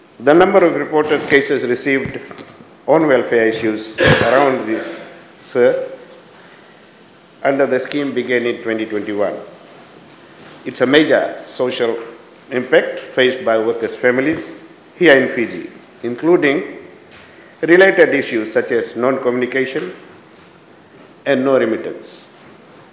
Employment Minister Agni Deo Singh while speaking in Parliament [Source: Parliament of the Republic of Fiji]